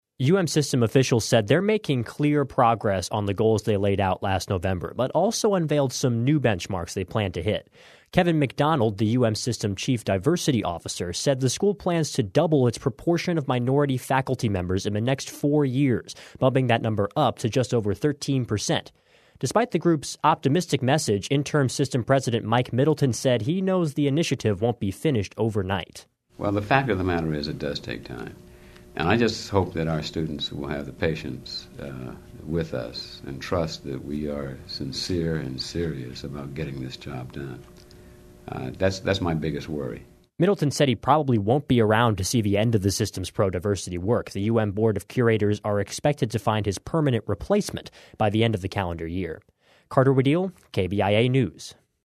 Fourth Place Radio - News and Features - Hearst Journalism Awards Program